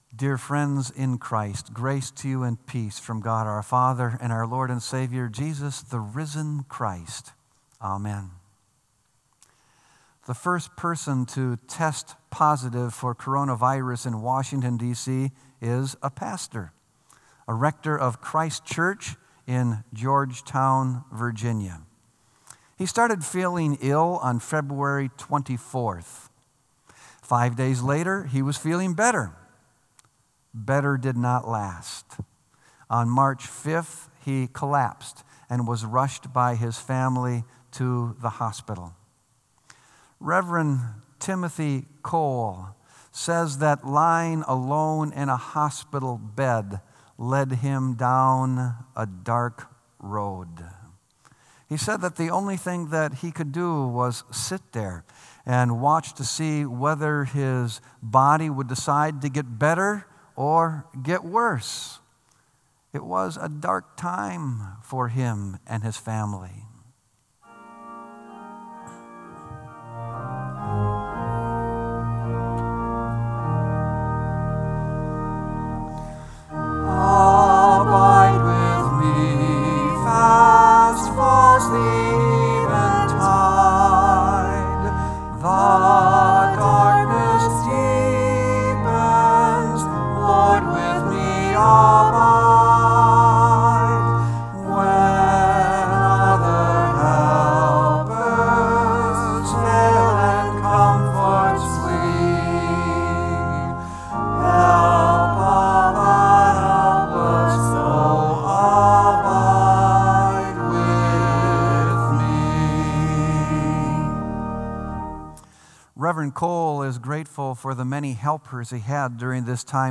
Sermon “Abide With Me”